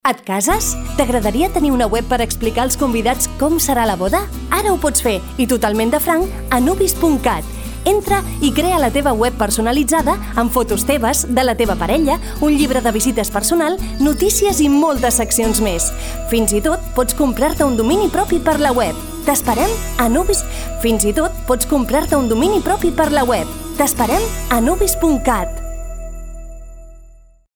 A continuació us presentem les falques de publicitat que s’han emès a ràdio Flaix Bac: